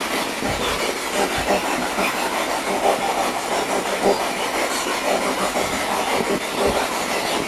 Spirit Box Clip 10 Villisca Axe Murder House Spirit Box Clip 10 This is my favorite spirit box response of the night! As I was walking downstairs after session, a young child, amazingly, is heard behind the "white noise" of the SB11 singing what sounds like a complete line or two from a song!!
The singing starts about 1/2 second in and goes all the way to the end! The kid starts off singing high notes, then he gradually goes to lower notes in the second half of the clip.